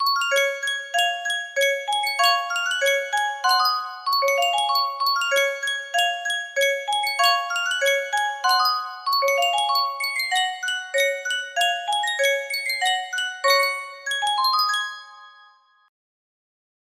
Sankyo Music Box - The Marines' Hymn LQ music box melody
Full range 60